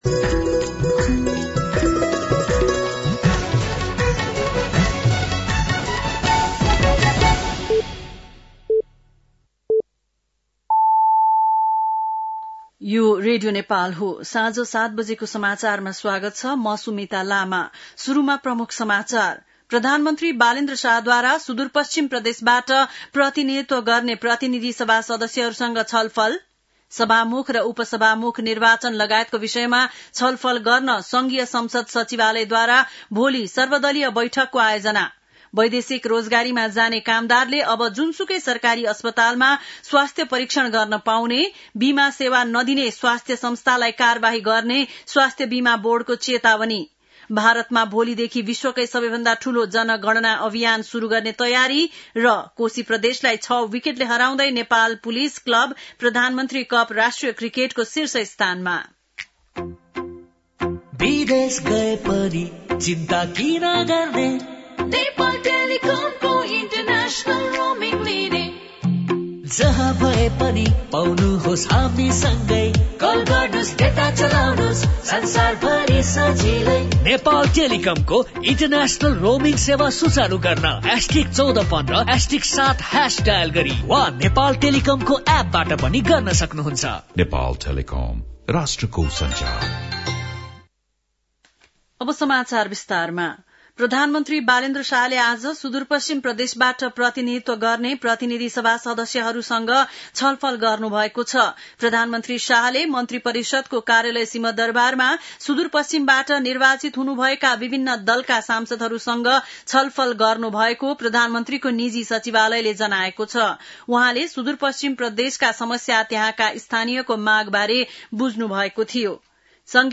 बेलुकी ७ बजेको नेपाली समाचार : १७ चैत , २०८२